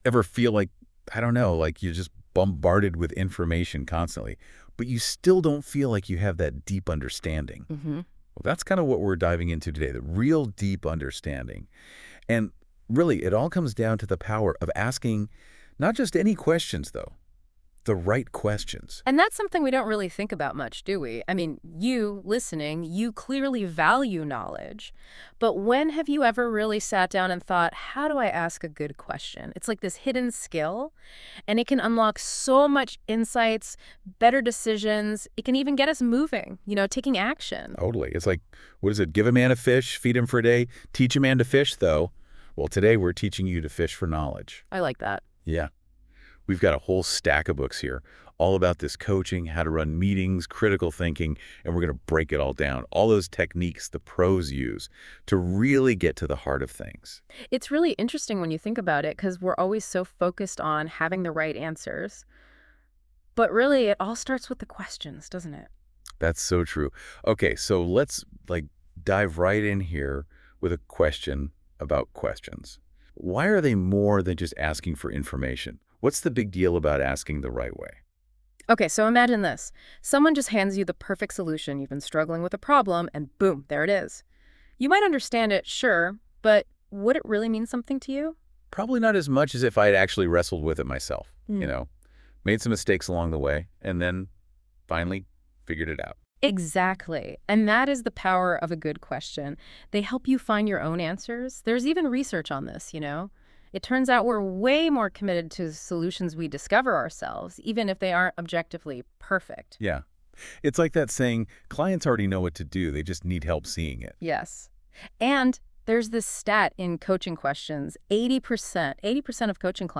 AI-assisted creation